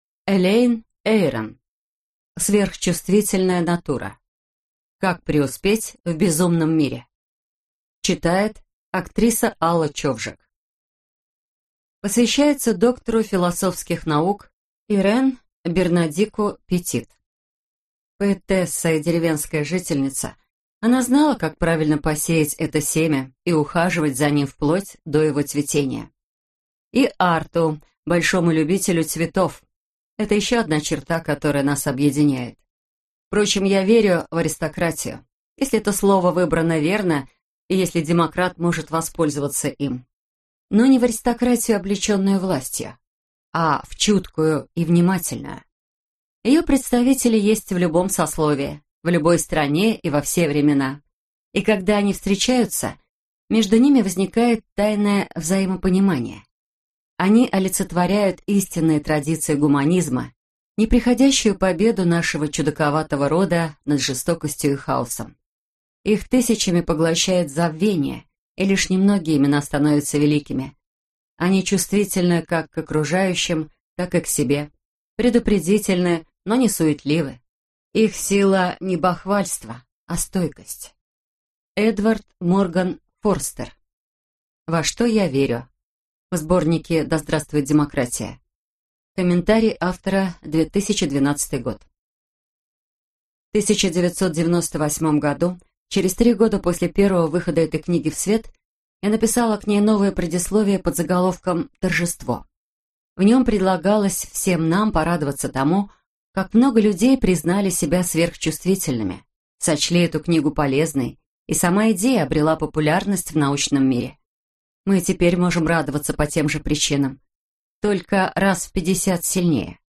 Аудиокнига Сверхчувствительная натура. Как преуспеть в безумном мире | Библиотека аудиокниг